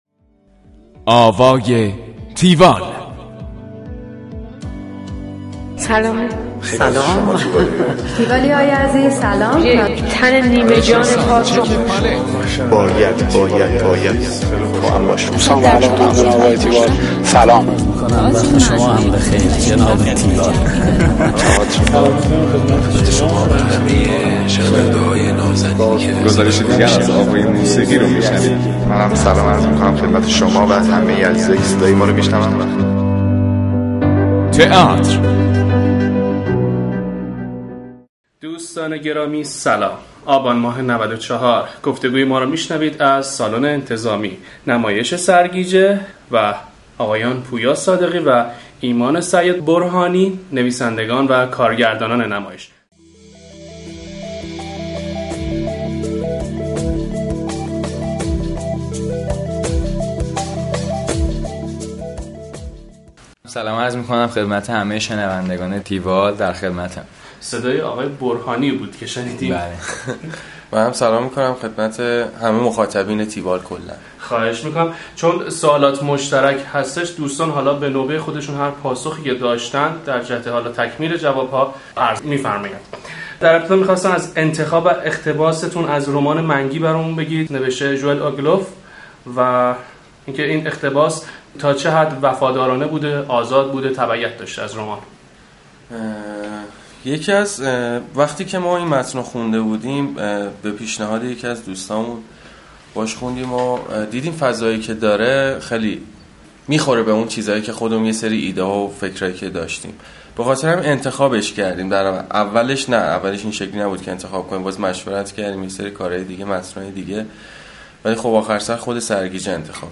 tiwall-interview-sargije.mp3